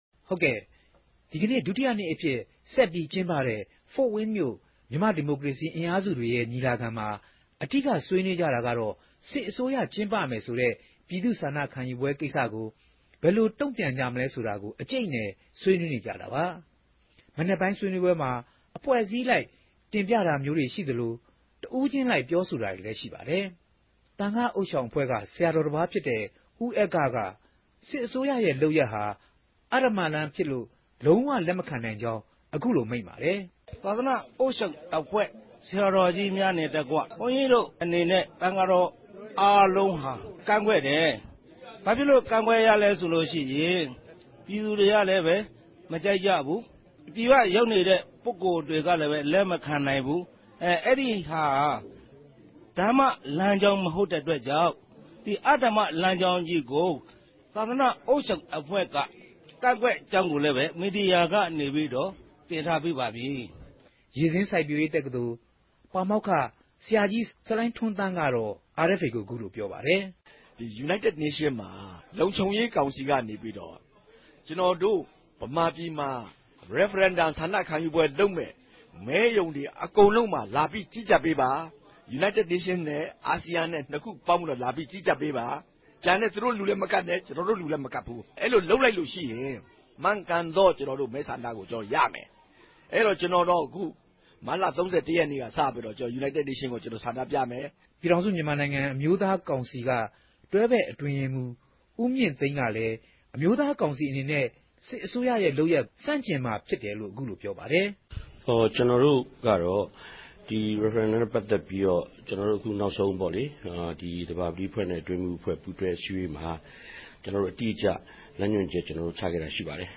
သတင်းပေးပိုႛခဵက်ကို နားဆငိံိုင်ပၝသေးတယ်။